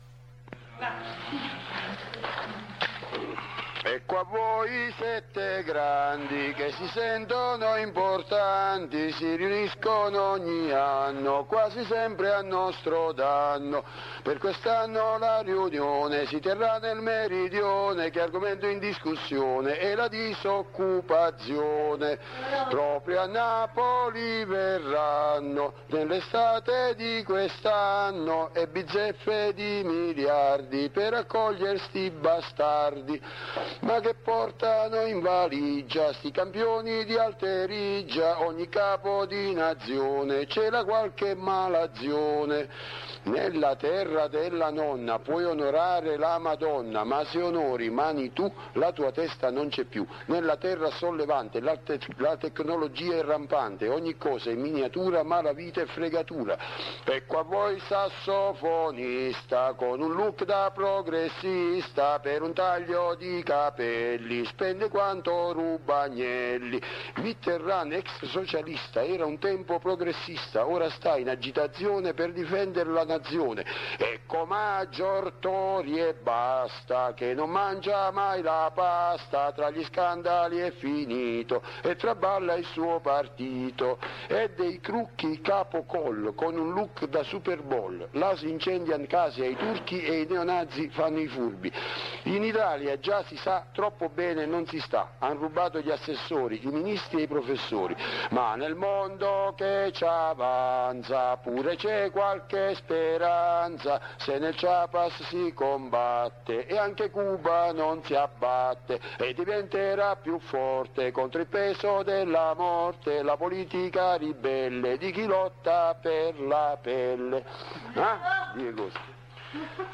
12° Carnevale